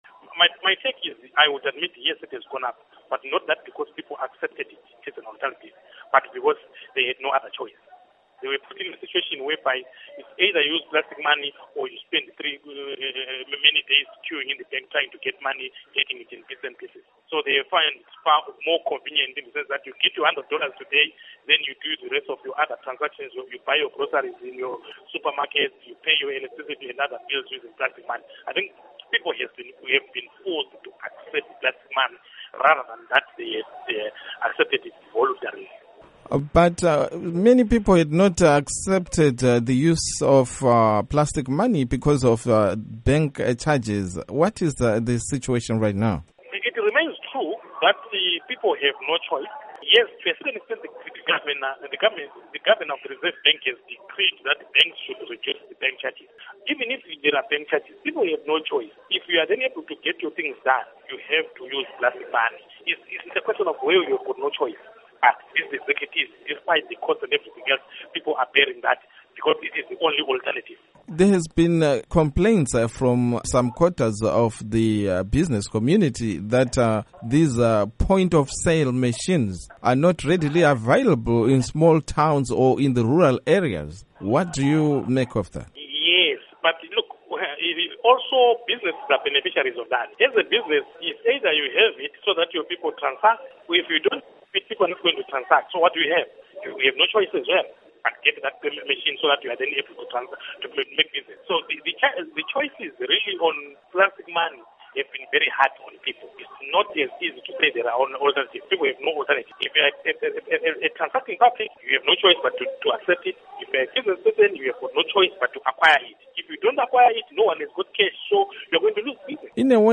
Onterview